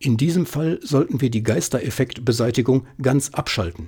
Hello, now i have tried most of the tips from you and from the manual in my newest voice-over recording session with the Blue Yeti USB microphone, but the click sounds (with Win 10) remain unchanged (perhaps slightly less frequent, but perhaps just as frequent, certainly with same volume and characteristics).
mono recording
Apart from the click sounds, i get very nice results – very clear voice, no hiss, solid silence in breaks.